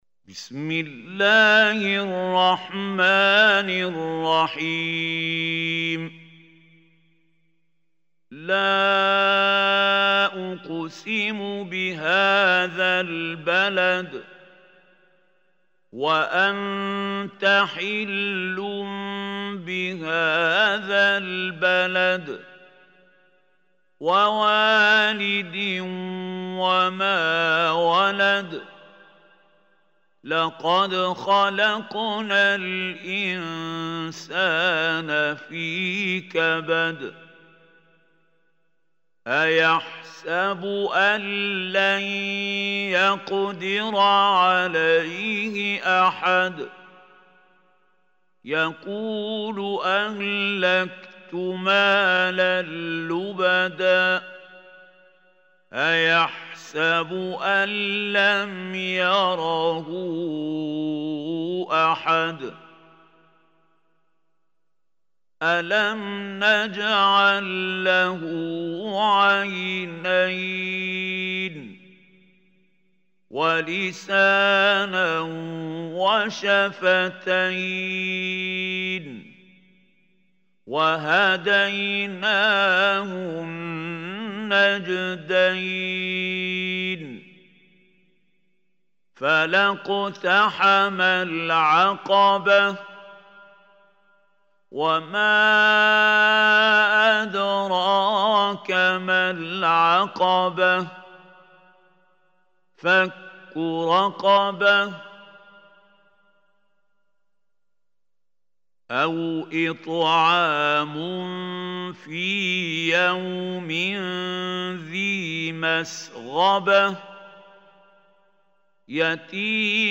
Surah Al-Balad MP3 Recitation by Khalil Hussary
Surah Al-Balad is 90 surah of Holy Quran. Listen or play online mp3 tilawat / recitation in Arabic in the beautiful voice of Sheikh Mahmoud Khalil Hussary.